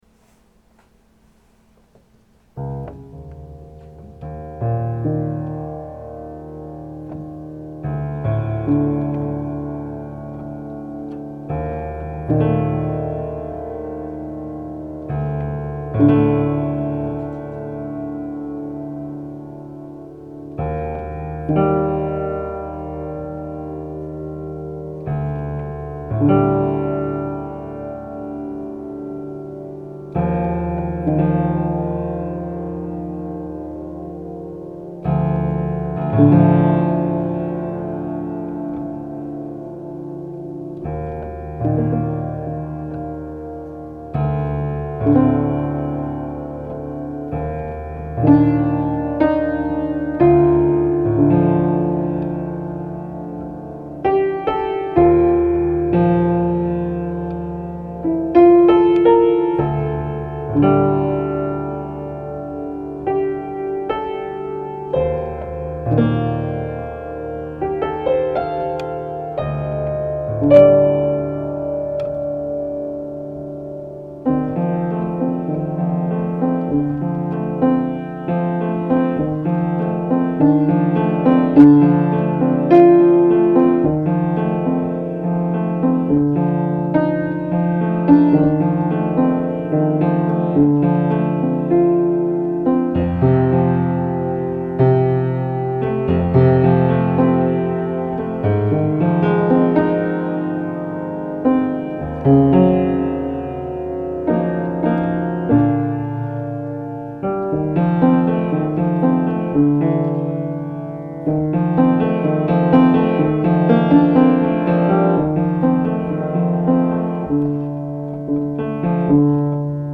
These are more piano improvisations on my 120 year old Beckwith piano, late at night, bored with nothing to do.